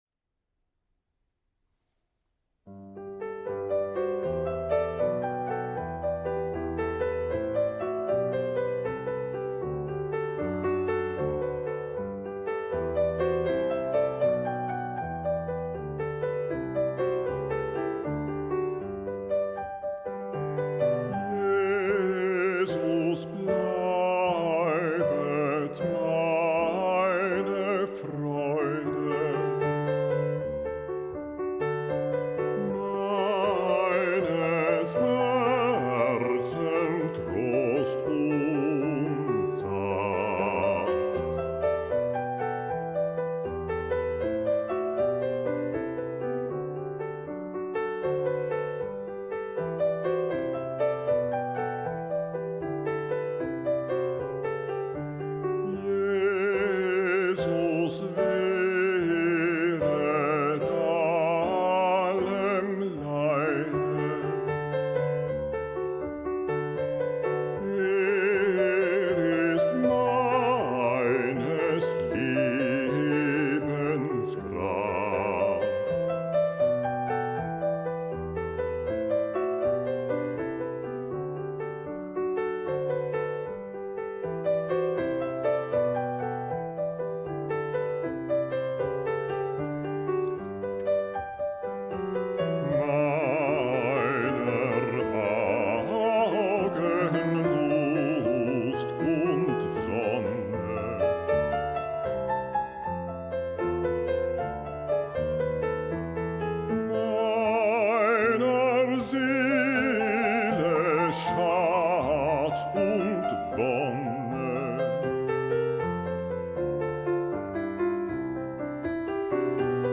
Lo ULTIMO Bajos
Bach-Jesus-bleibet-meine-Freude-basstemme-SYNG-med-DR-Vokalensemblet.mp3